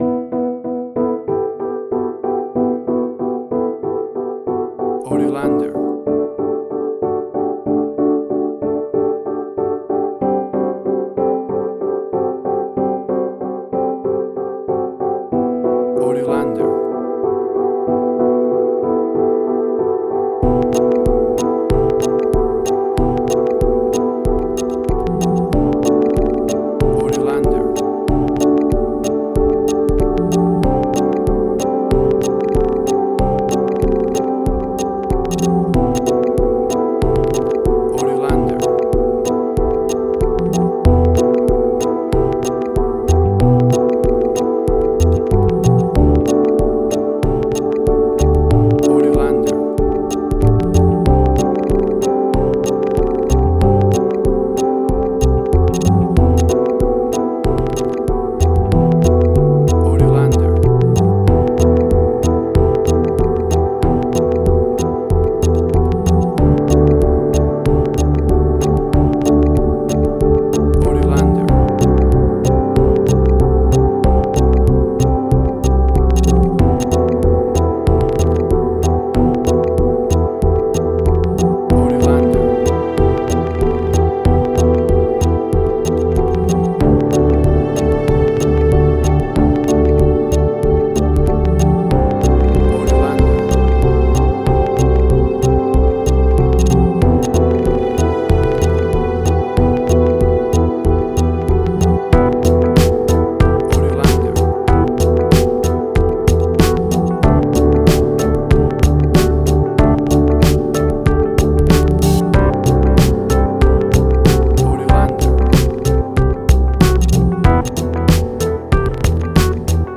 IDM, Glitch.
WAV Sample Rate: 16-Bit stereo, 44.1 kHz
Tempo (BPM): 94